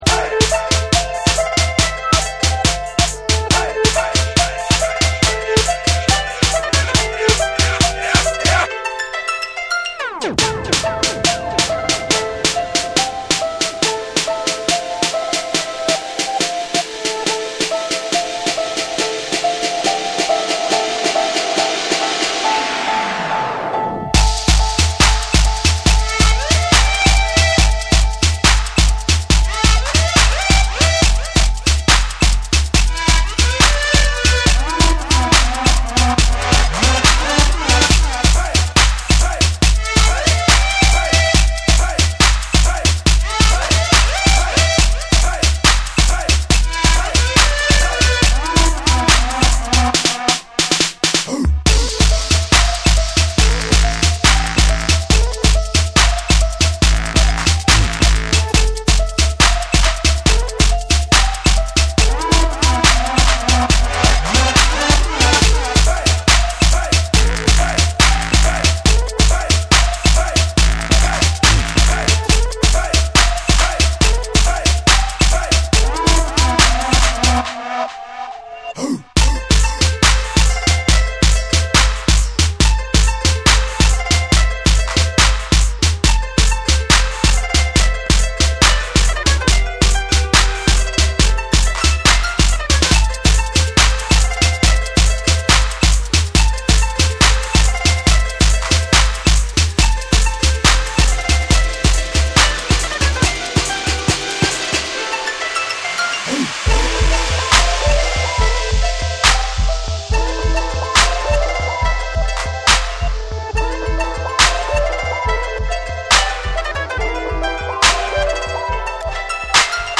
BREAKBEATS / DUBSTEP